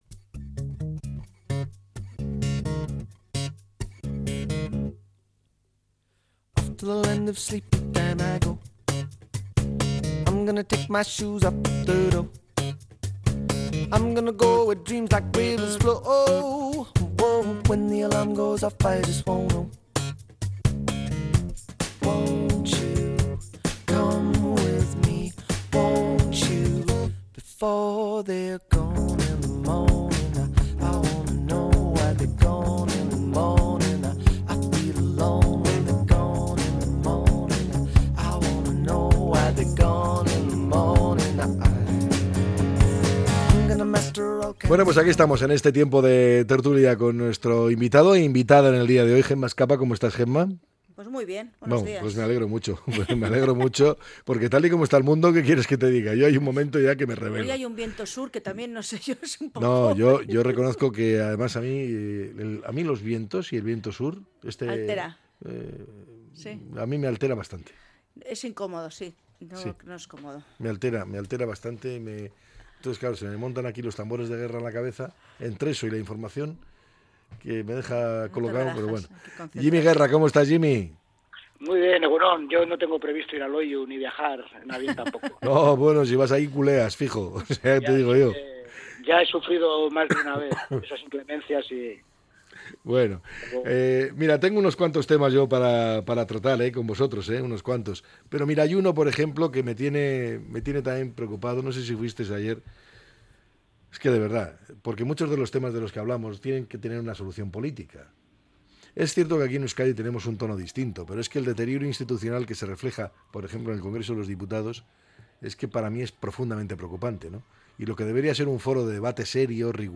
La tertulia 20-03-25.